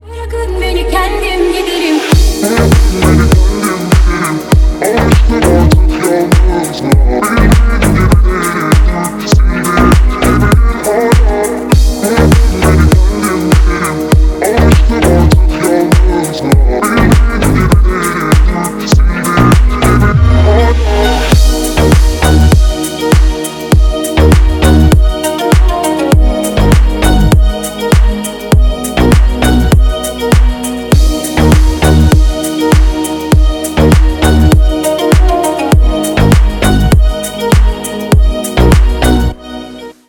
восточные клубные зарубежные